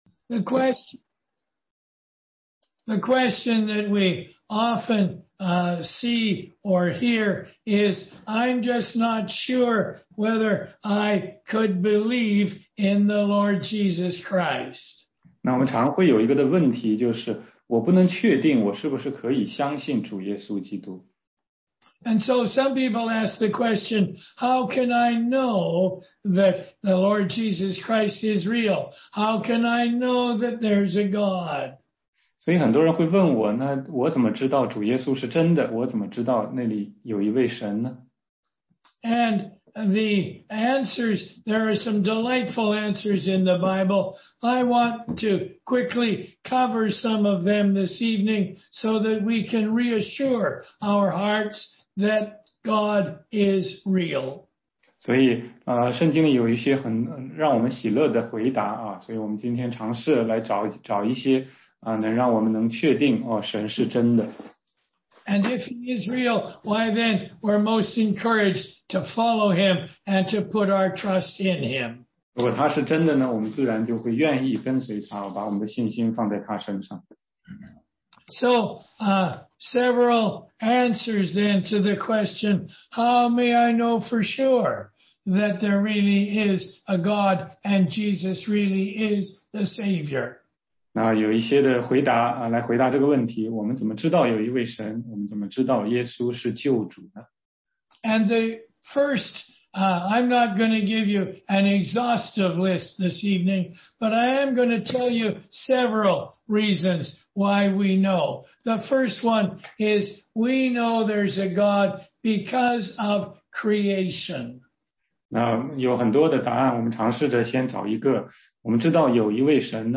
16街讲道录音 - 福音课第三十六讲